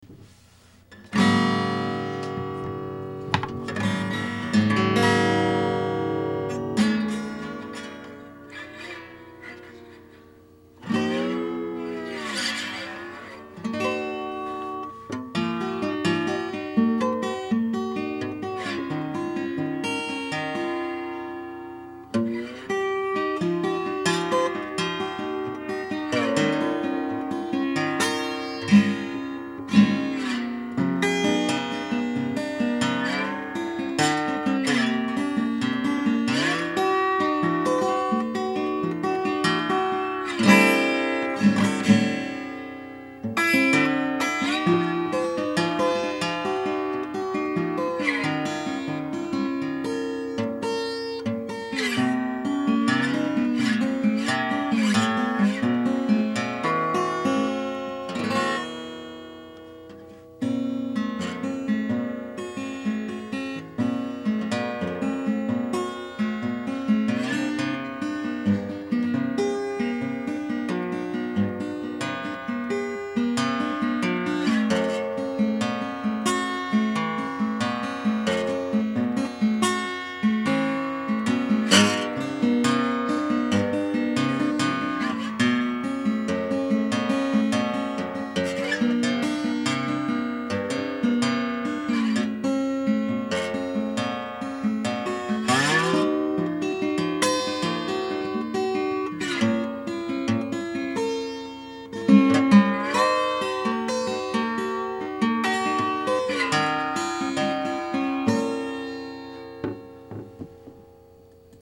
I discovered that the huge gently curved neodymium magnet clung to the strings in just the right way to create this wonderfully twangy slide-guitar sort of sound.
magnetic-guitar.mp3